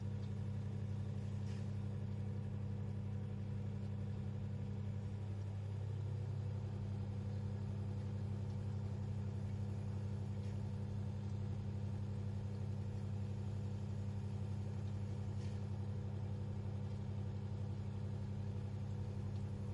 冰箱超薄高格拉斯前门打开莱昂罗德
描述：冰箱振动嗡嗡声
Tag: 振动 冰箱 哼着